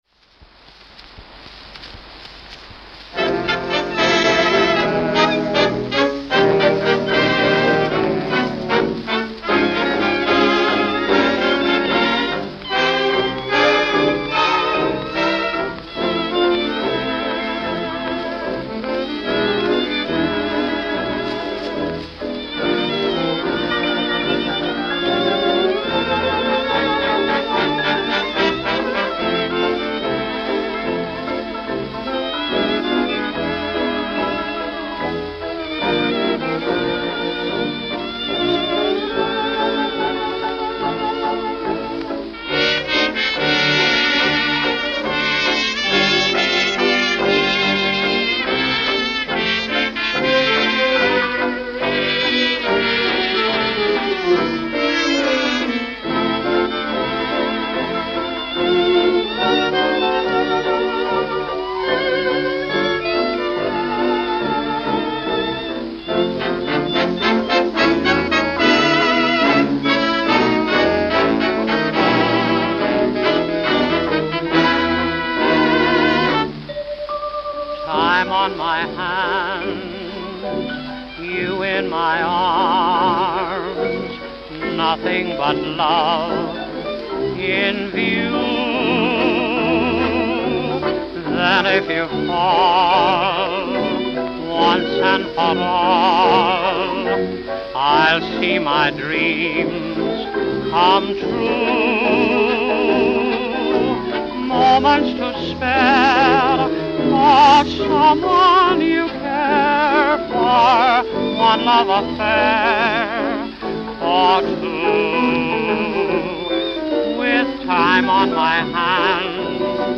New York City